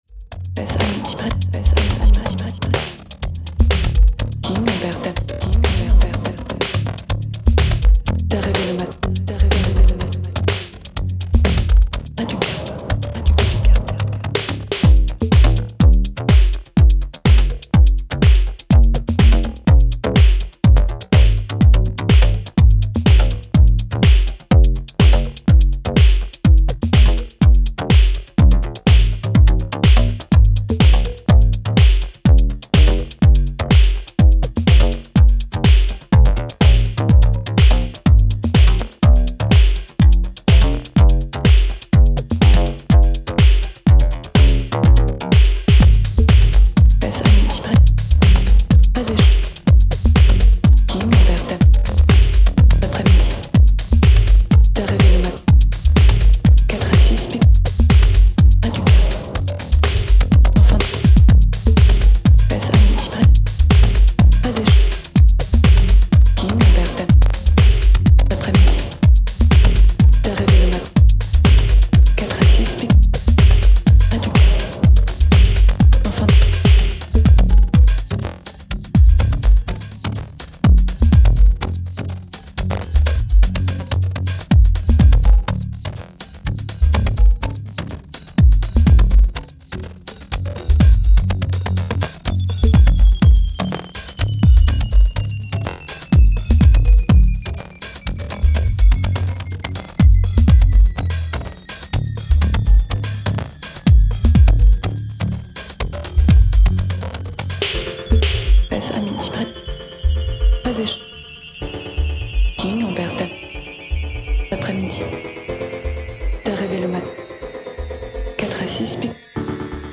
two minimal groovers.
House Minimal